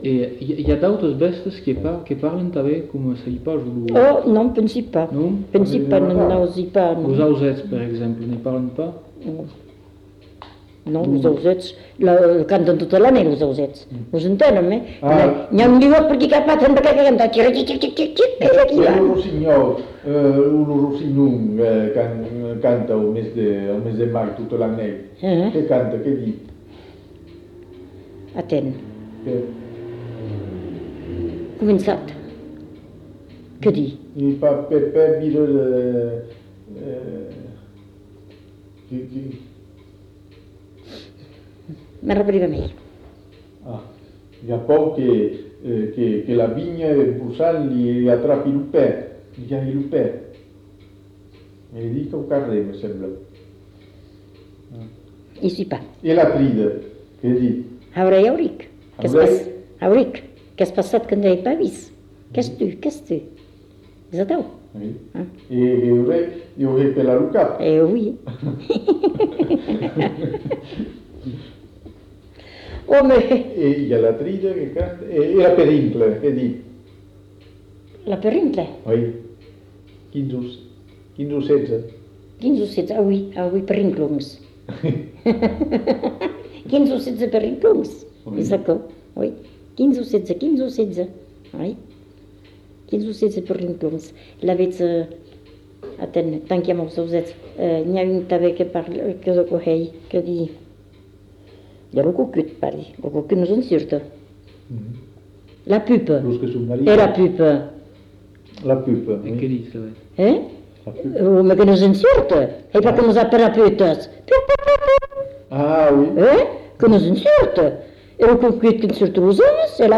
Aire culturelle : Bazadais
Lieu : Cazalis
Genre : forme brève
Effectif : 1
Type de voix : voix de femme
Production du son : récité
Classification : mimologisme